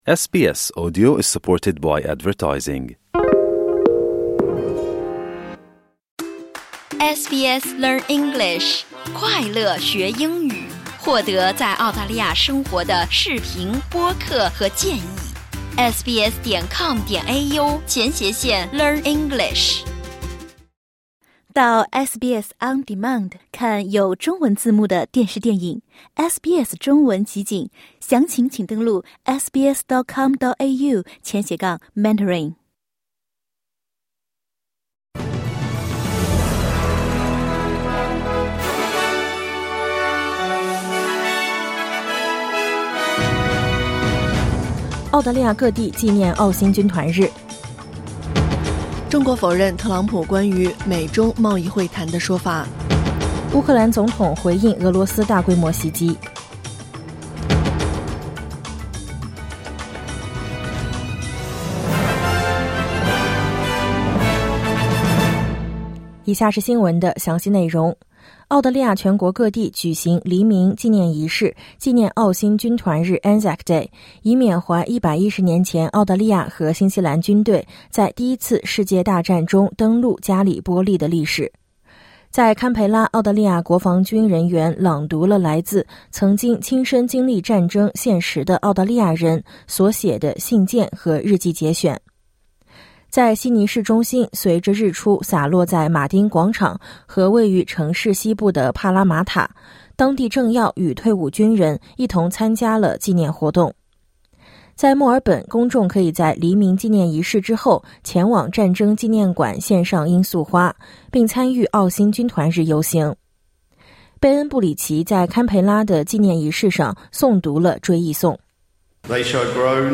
SBS早新闻（2025年4月25日）